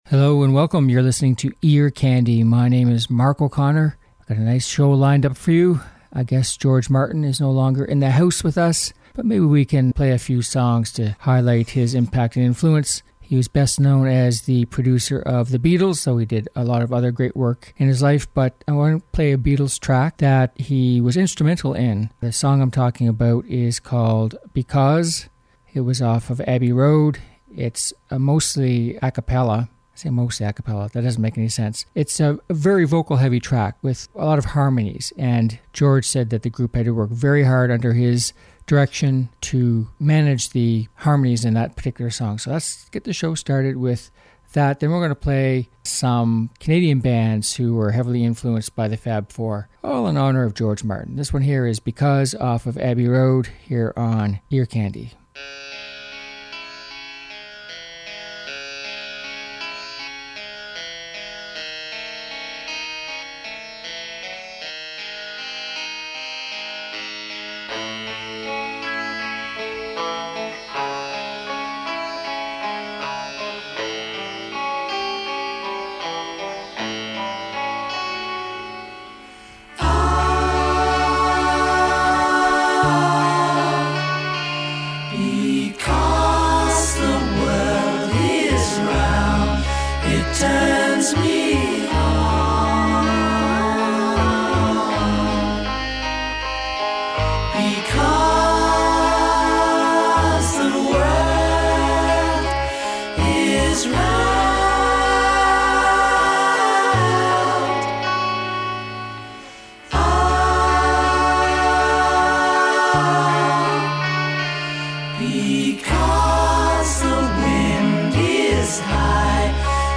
Upbeat Pop Songs